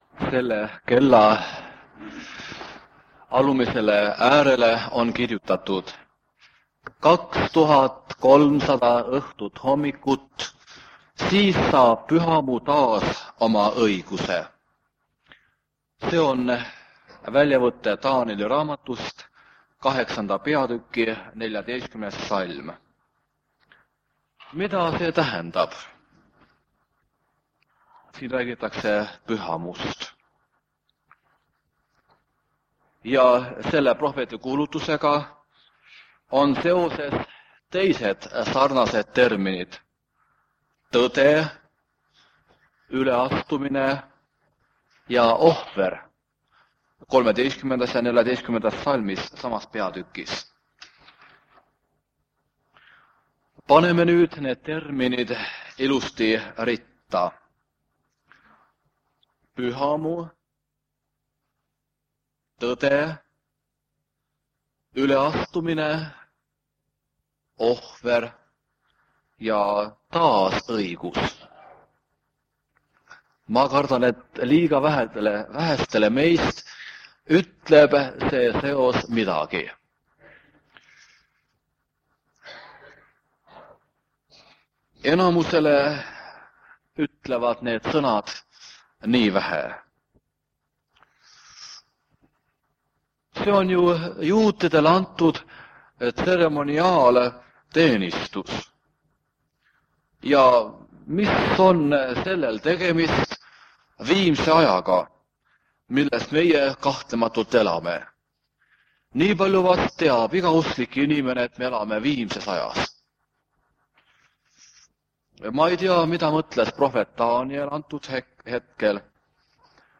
On ka mõned muusika-ettekanded.
Jutlused
Vanalt lintmaki lindilt koosolek Taanieli raamatu teemadel.